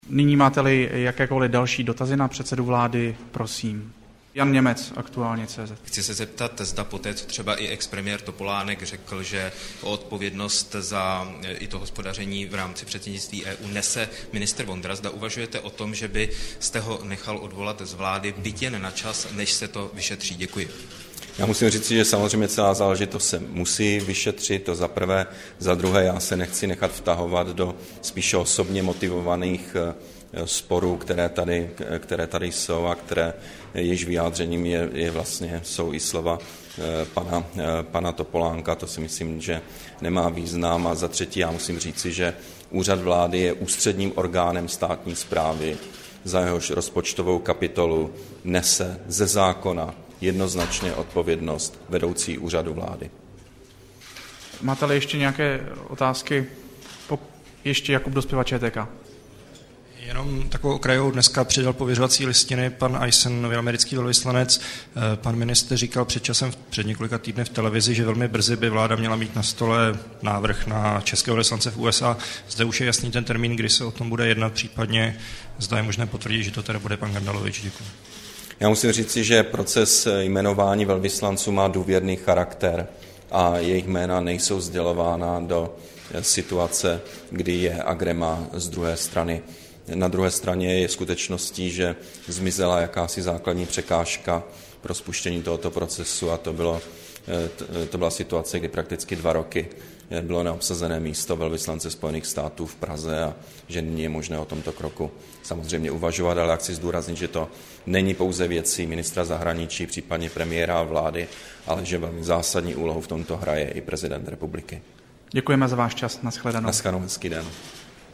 Premiér Petr Nečas zodpovídá dotazy novinářů, 28. ledna 2011